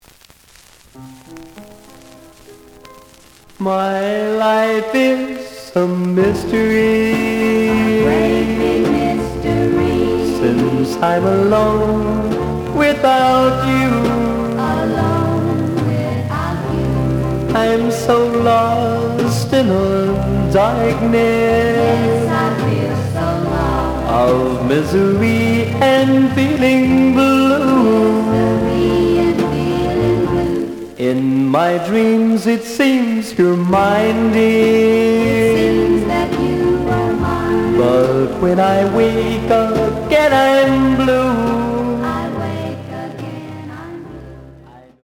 試聴は実際のレコードから録音しています。
●Genre: Rhythm And Blues / Rock 'n' Roll
●Record Grading: VG~VG+ (盤に若干の歪み。プレイOK。)